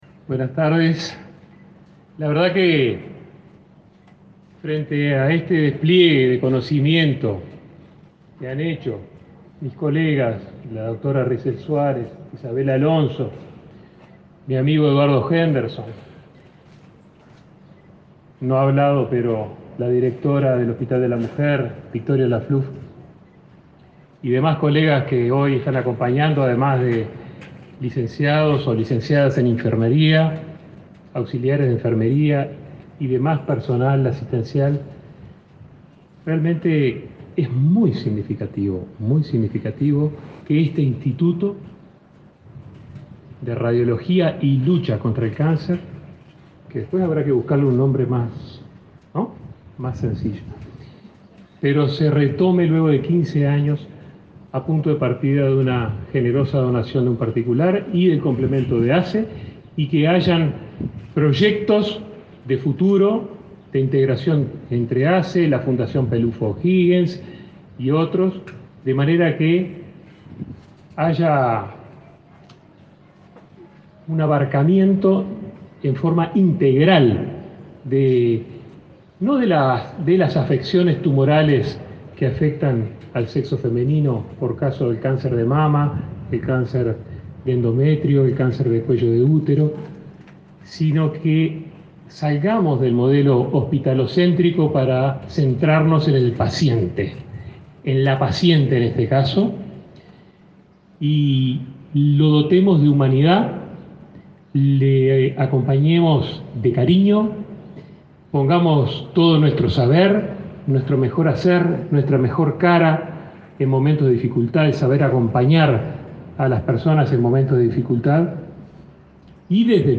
Palabras del ministro de Salud Pública
El ministro de Salud Pública, Daniel Salinas, participó de la inauguración del nuevo sector de policlínicas de oncología ginecológica en el hospital